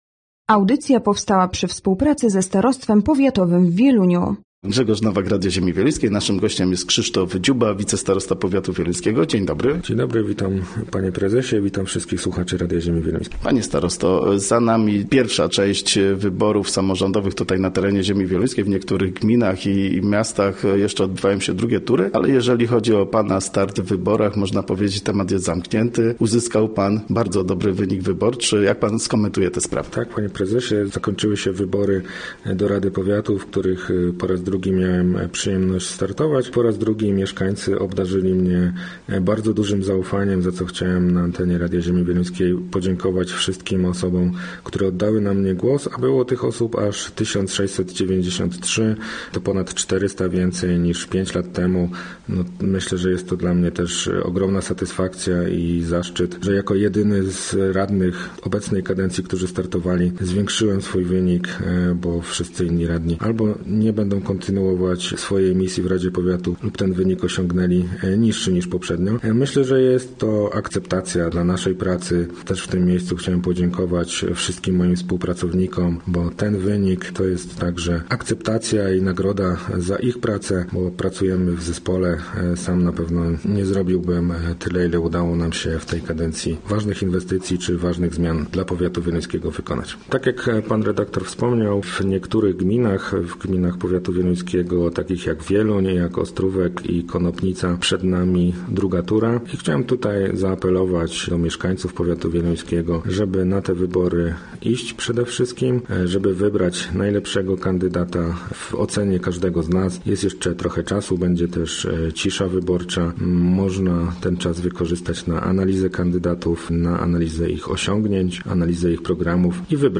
Gościem Radia ZW był Krzysztof Dziuba, wicestarosta powiatu wieluńskiego